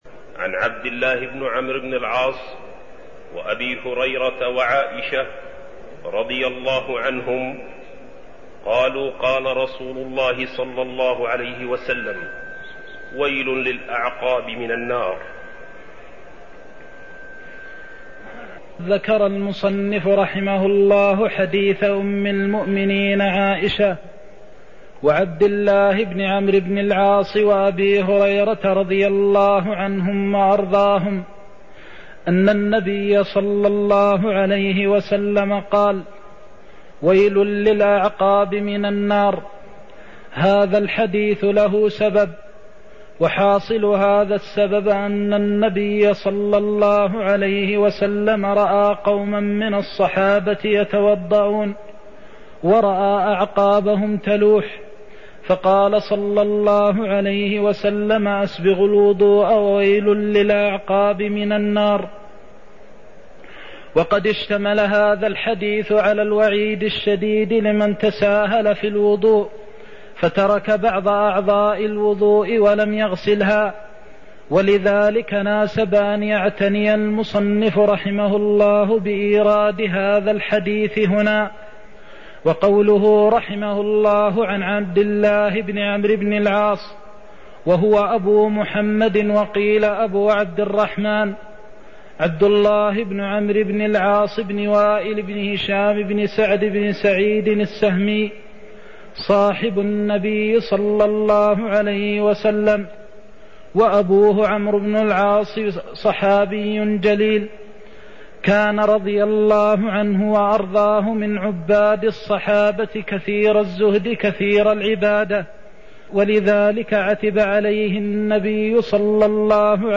المكان: المسجد النبوي الشيخ: فضيلة الشيخ د. محمد بن محمد المختار فضيلة الشيخ د. محمد بن محمد المختار ويل للأعقاب من النار (03)